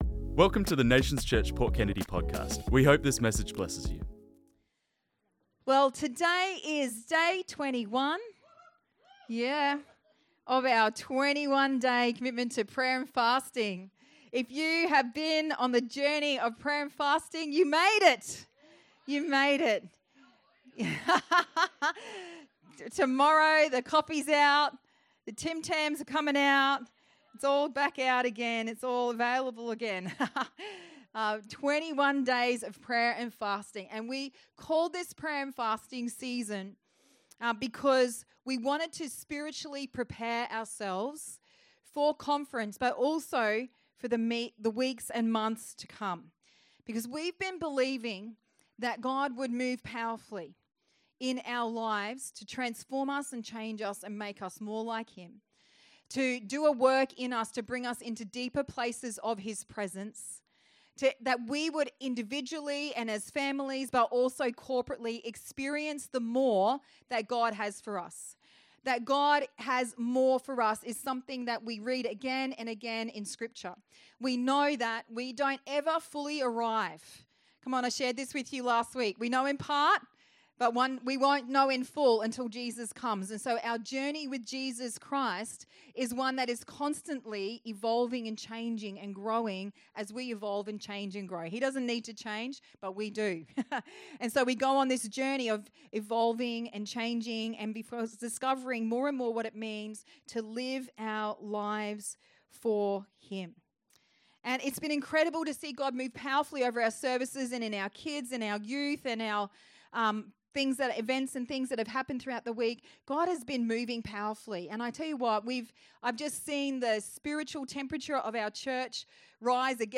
This message was preached on Sunday 7th July 2025